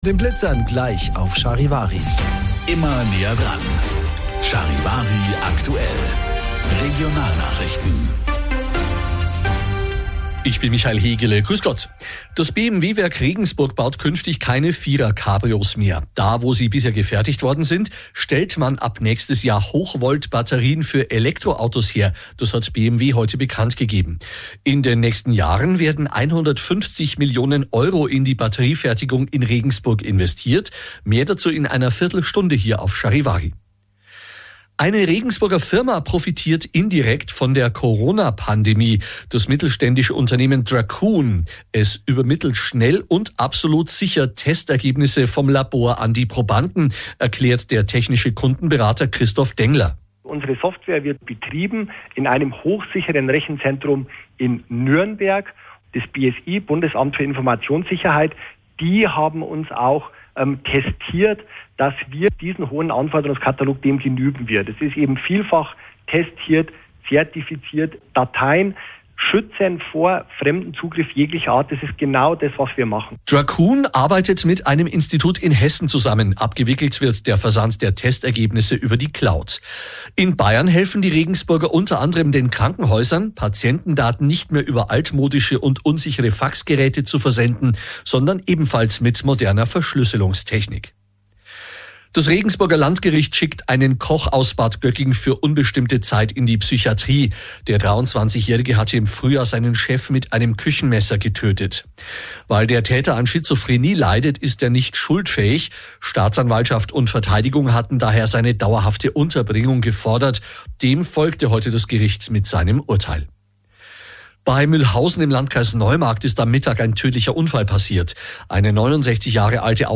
Der Beitrag wurde aufgeteilt in zwei Sendungen ausgestrahlt.